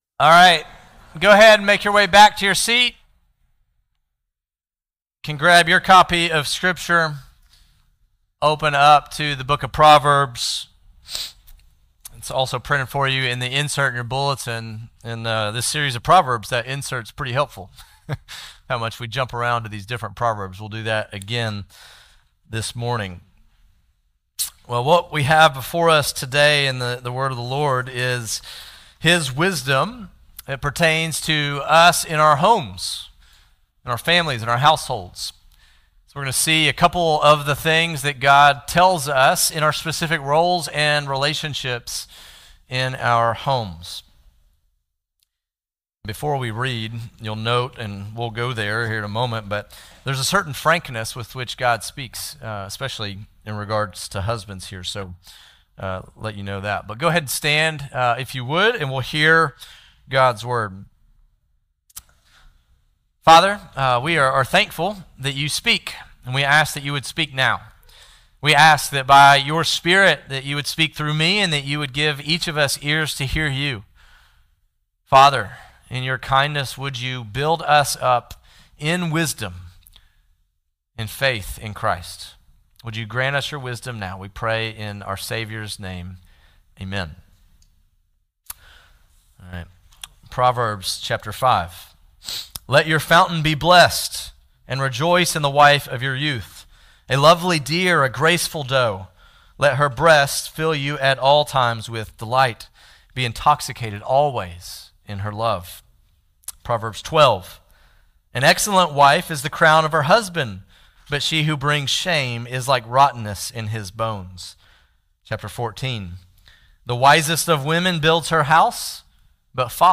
15.5 Service Type: Sermons « Friendship from God.